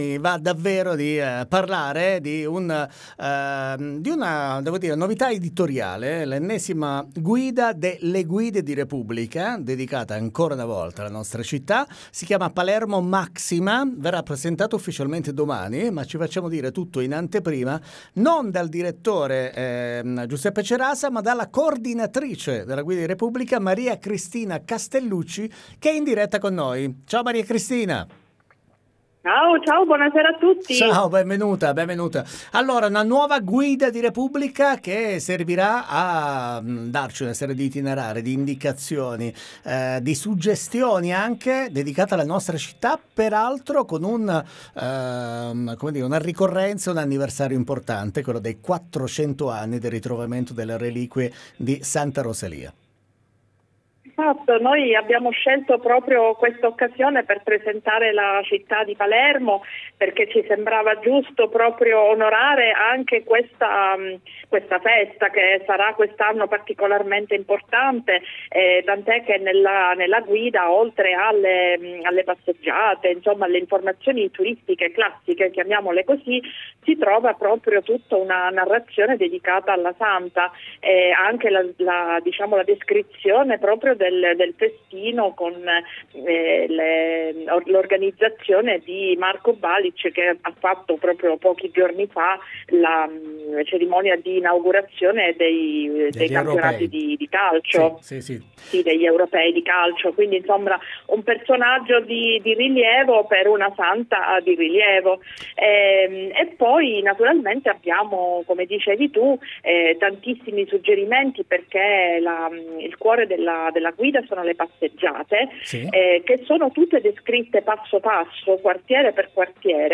PALERMO MAXIMA fast n curios Interviste 24/06/2024 12:00:00 AM